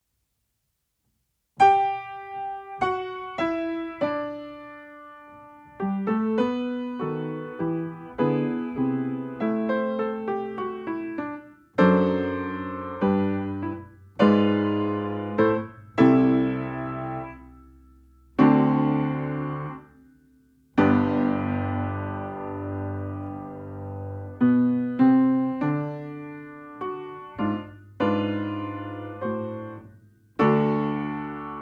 Allegro moderato: 100 BMP
Nagranie dokonane na pianinie Yamaha P2, strój 440Hz
piano